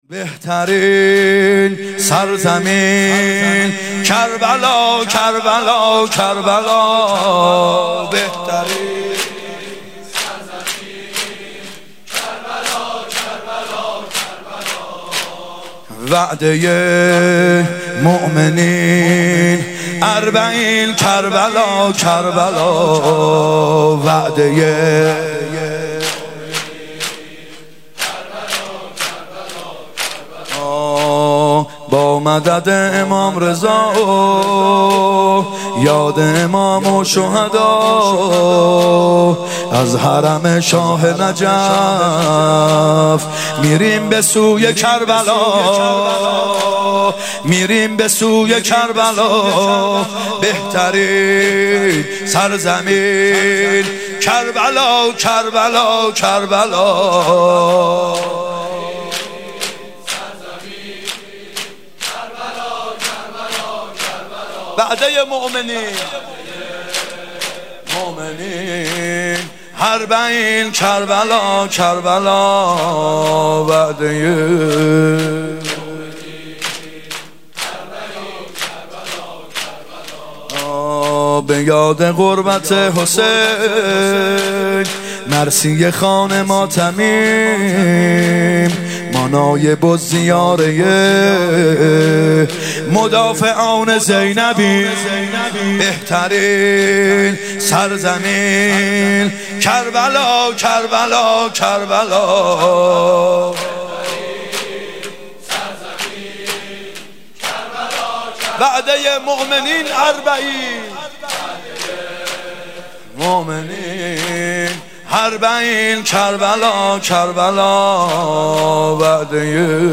محرم 96 شب ششم سه ضرب ( بهترین سرزمین کربلا کربلا کربلا